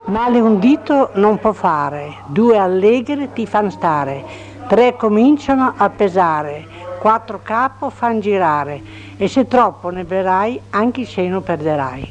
filastrocca - il vino.mp3